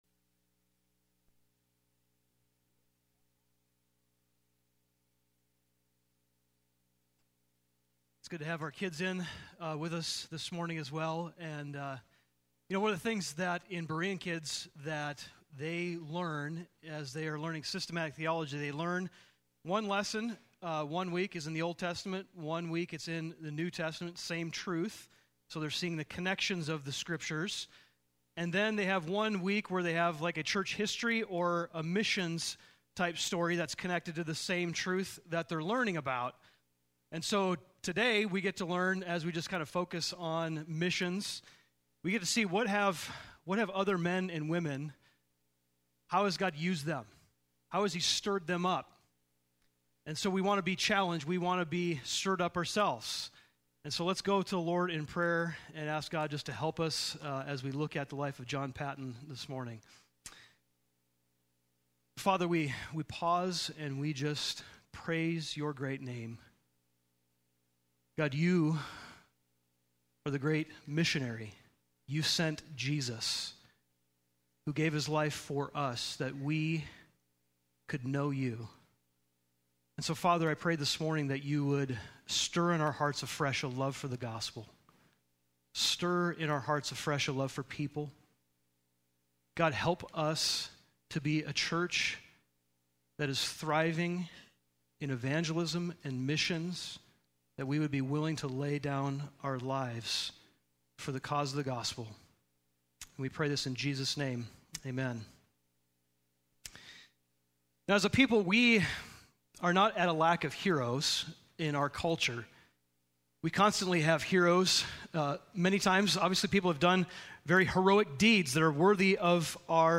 Service Type: Sunday Morning